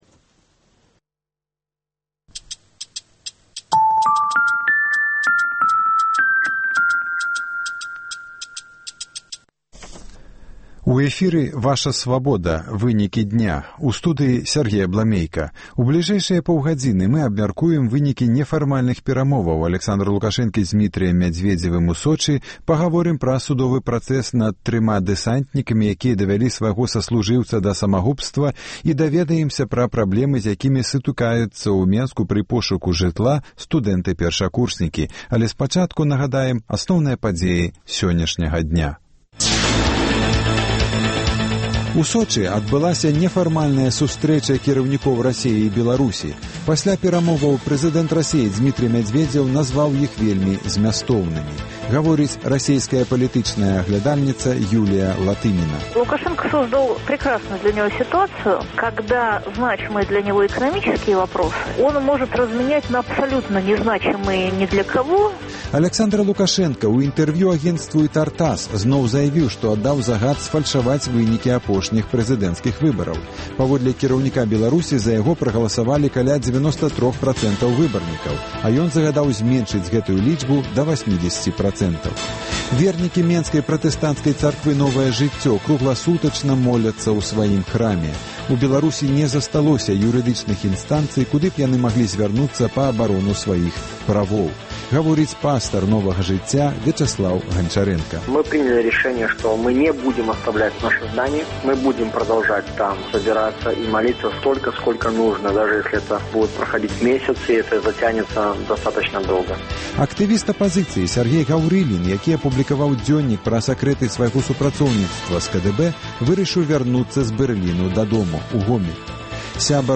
Паведамленьні нашых карэспандэнтаў, званкі слухачоў, апытаньні ў гарадах і мястэчках Беларусі